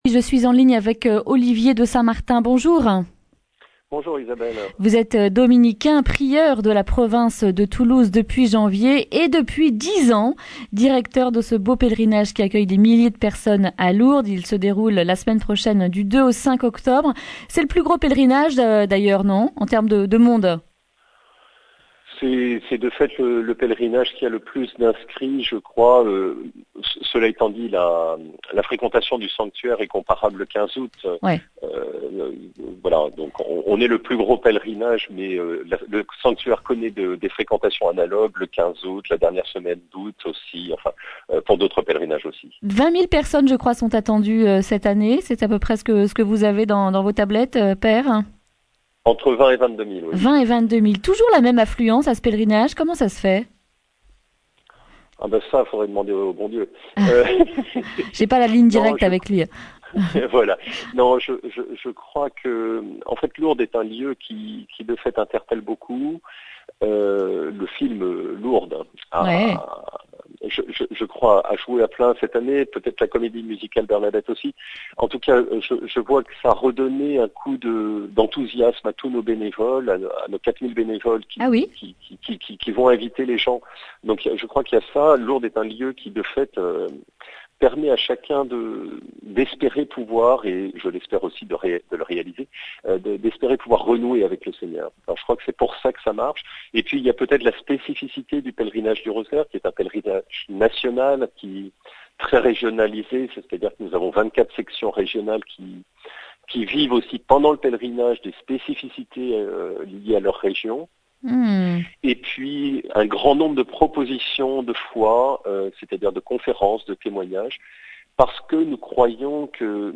vendredi 27 septembre 2019 Le grand entretien Durée 10 min